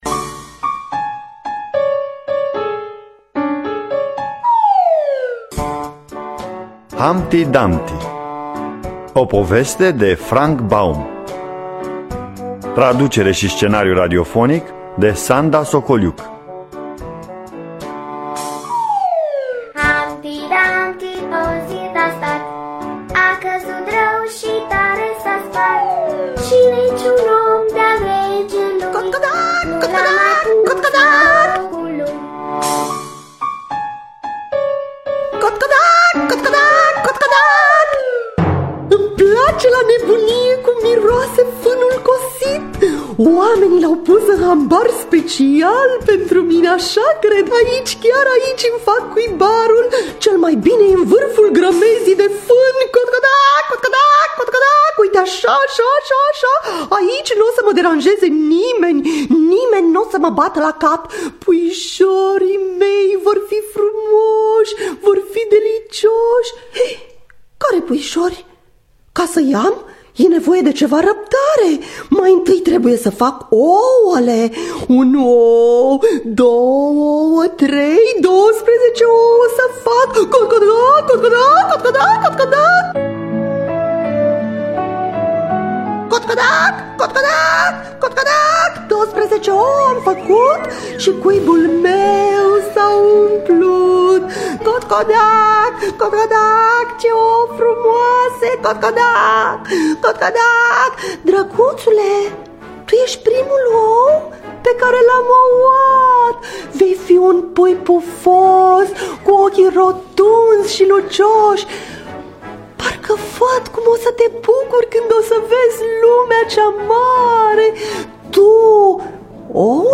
Traducerea și dramatizarea radiofonică